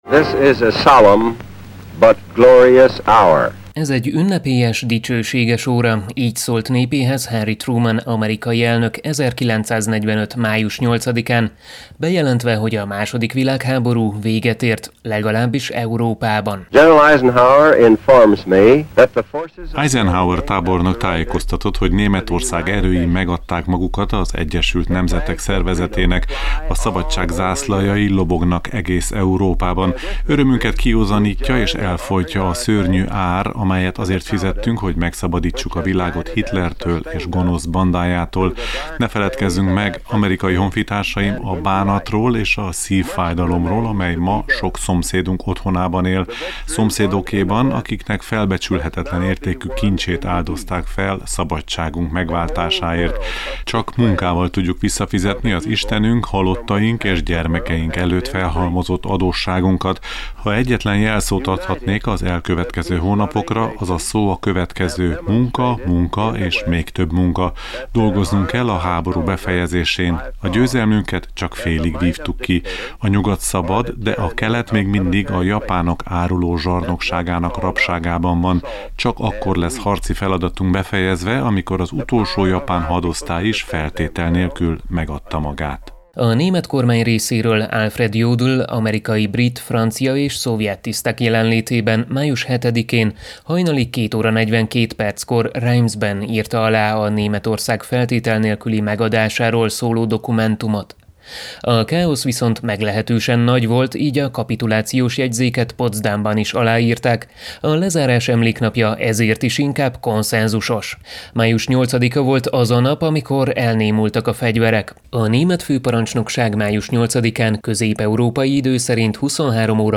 Történésszel tekintettük át az eseményeket.